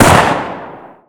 sol_reklam_link sag_reklam_link Warrock Oyun Dosyalar� Ana Sayfa > Sound > Weapons > P90 Dosya Ad� Boyutu Son D�zenleme ..
WR_fire.wav